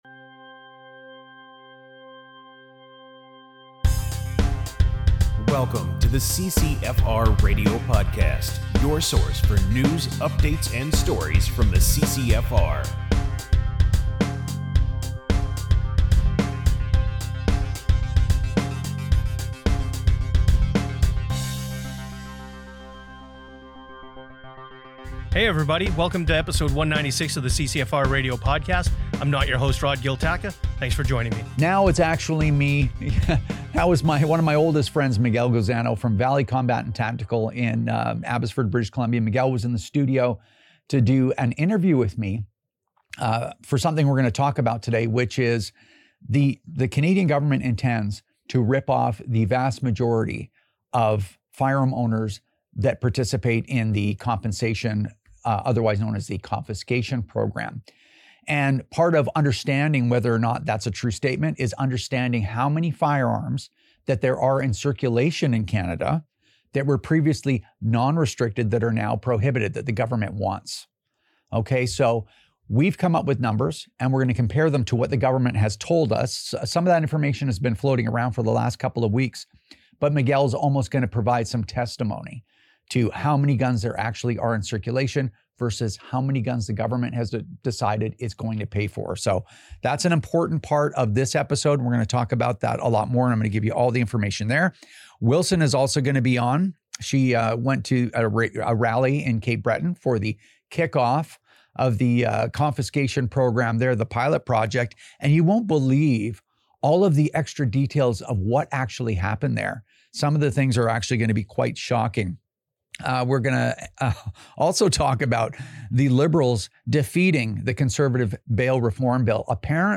Libs, NDP and Bloc vote down bail reform, so much for public safety. Interview